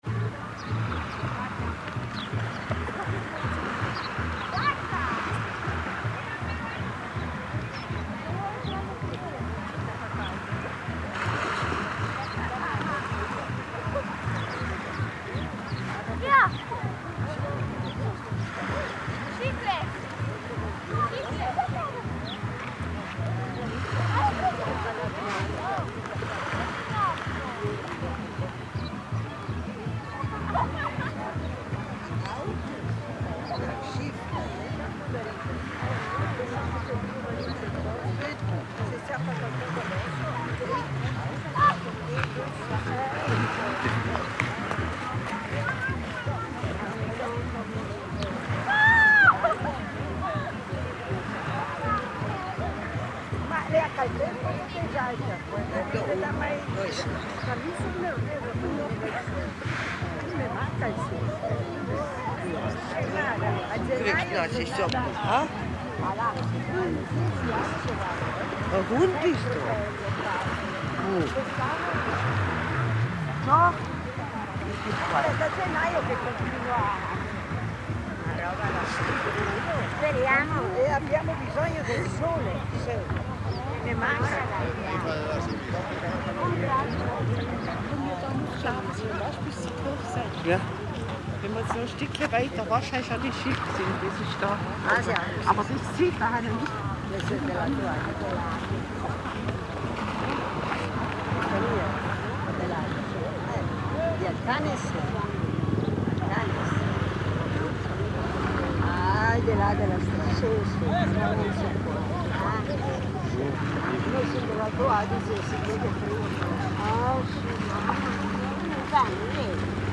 Camminiamo sulla spiaggia per raggiungere la Biblioteca Comunale A. Panzini dove a breve inizieranno gli ascolti e non possiamo fare a meno di fermarci e registrare…
bellariabassamarea.mp3